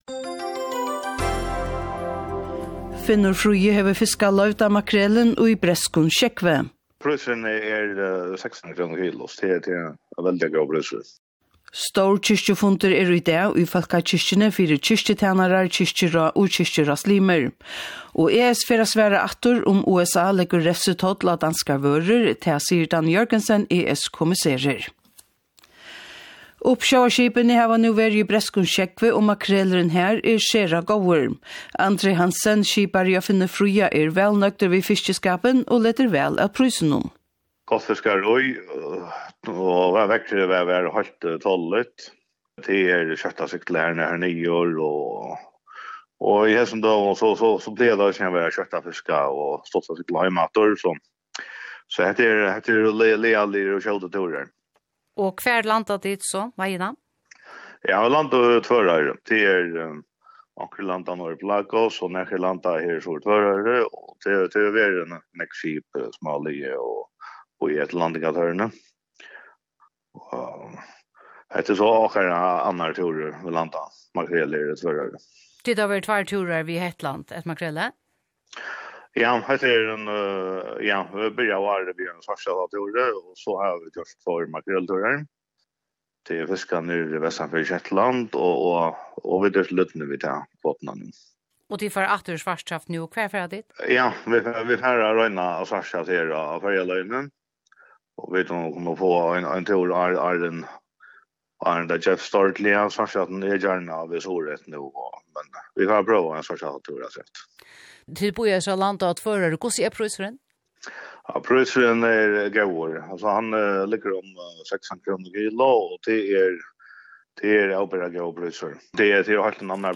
Gerandisdagar: Mánadag – fríggjadag, 10 sendingar frá klokkan 7:00 – 18:00 Vikuskifti: Leygardag og sunnudag, tríggjar sendingar frá klokkan 10:00 – 18:00 Tíðindasendingin í útvarpinum varpar ljós á samfelagið, og tíðindaflutningurin greinar og perspektiverar núlig evni í einum samansettum heimi.